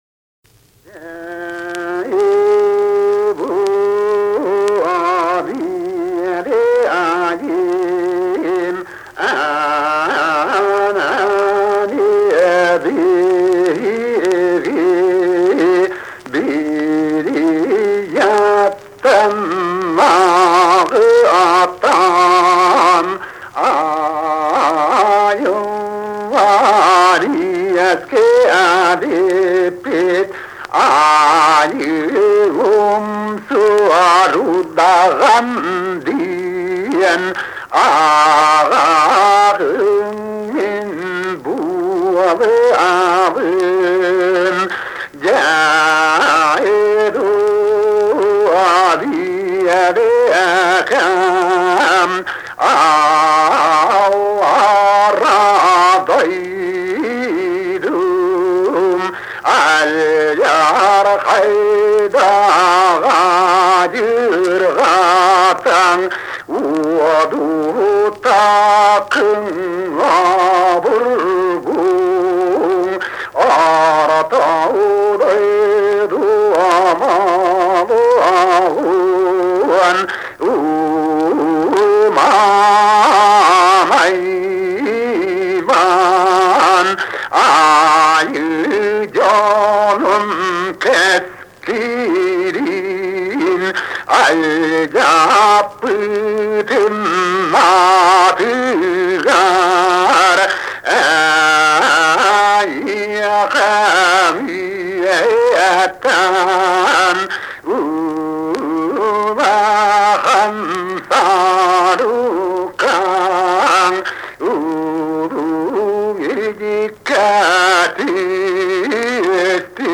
Якутский героический эпос "Кыыс Дэбилийэ"
Песня шаманки Айыы Умсуур из олонхо "Ньургун Боотур Стремительный".